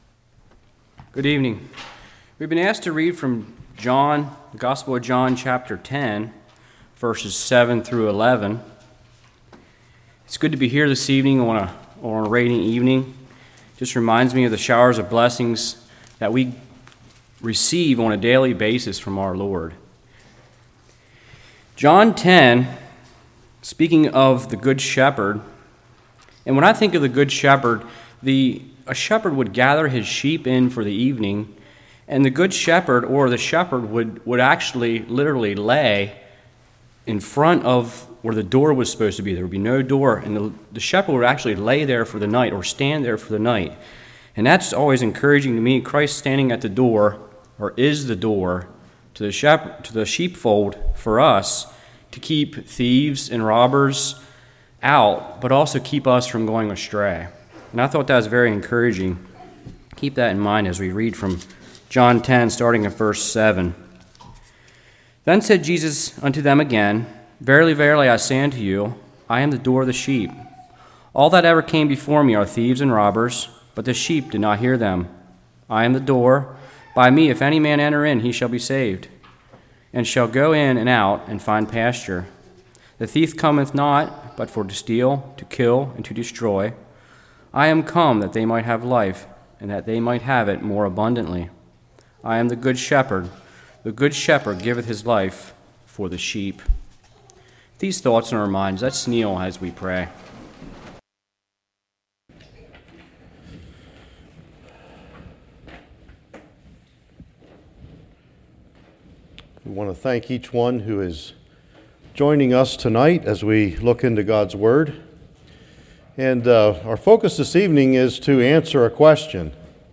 7-11 Service Type: Evening Self Love vs. Self Denial Why Some Christians Are Teaching Self Love.